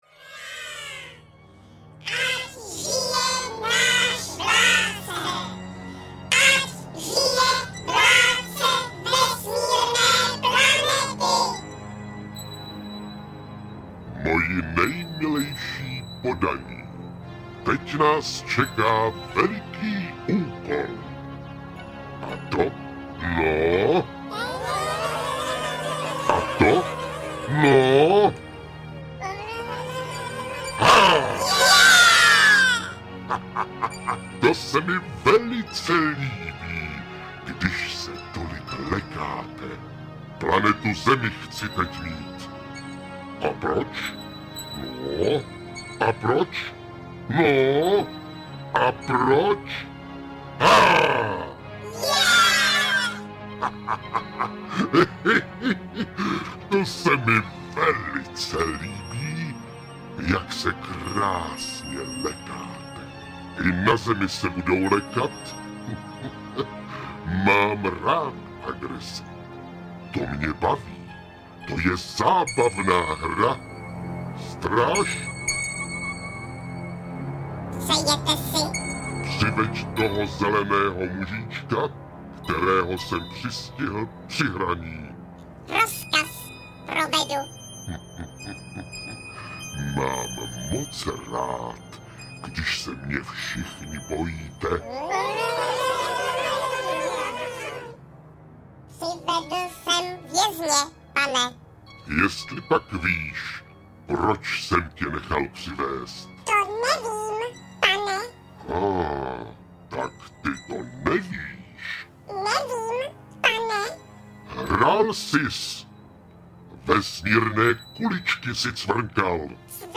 Hurvínkovy veselé příběhy audiokniha
Sestava přináší nahrávky s interprety Helenou Štáchovou a Martinem Kláskem.
Ukázka z knihy
Alba Hurvínkovo chichotání 2 a Hurvínkovo chichotání 3 jsou plné humoru a smíchu a obsahují spoustu vtipů, legrácek a veselých hádanek. S novým obalem a v remasterované podobě se vrací do prodeje rozmanité příběhy, které jsou nyní poprvé v nabídce digitálních obchodů.